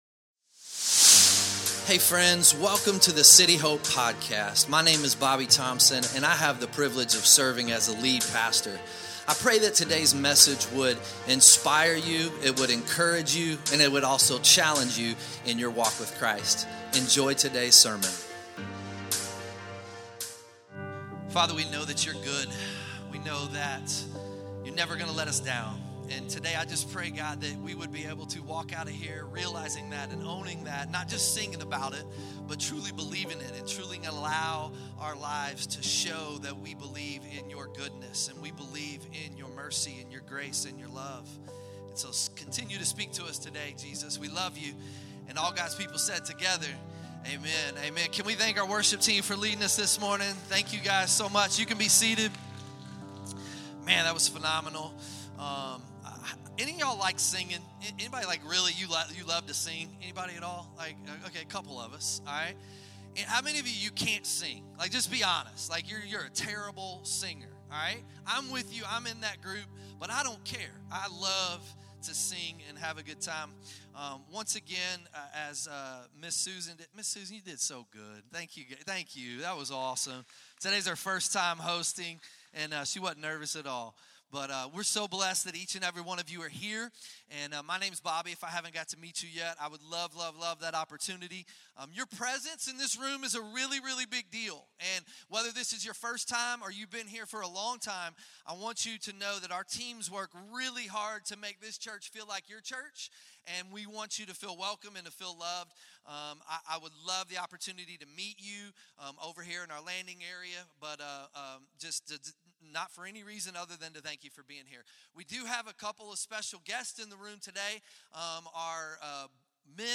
2026 Sunday Morning Can you imagine living in such a way…that your obedience inspires people long after you’re gone?